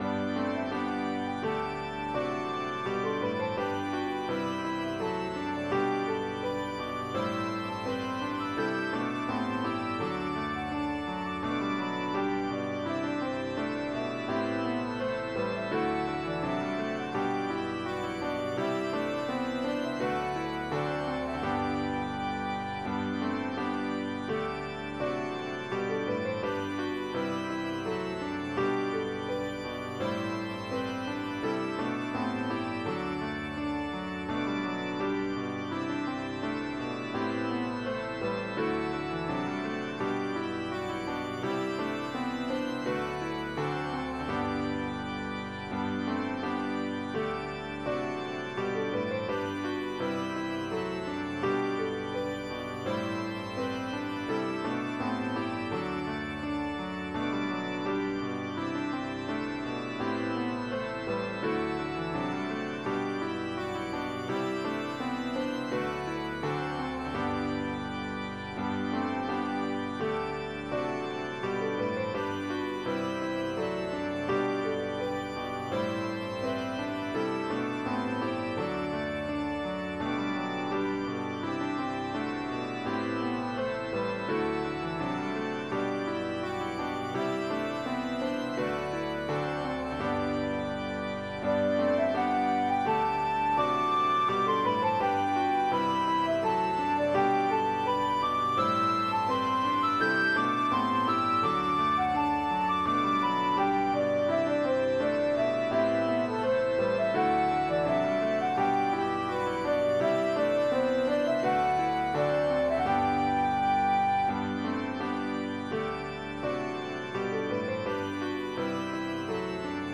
Koe ik alle talen (bas)